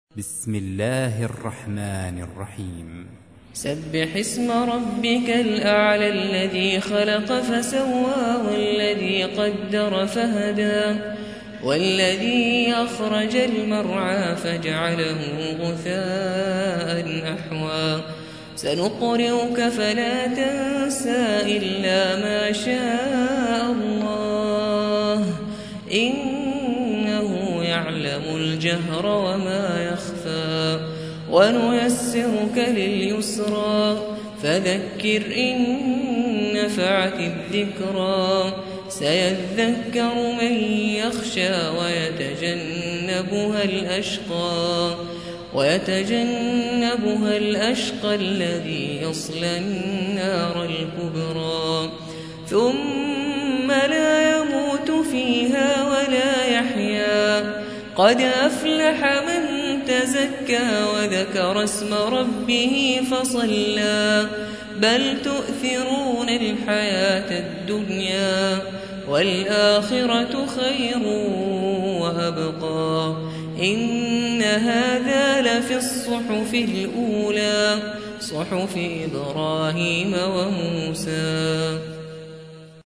87. سورة الأعلى / القارئ